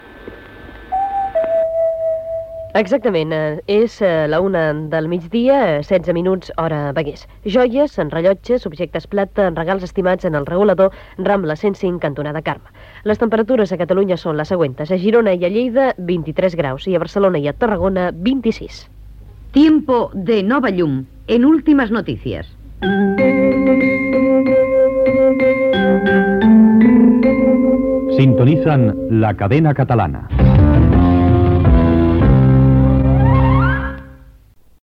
Toc de l'hora, publicitat, temperatures, patrocini del butlletí i careta
Informatiu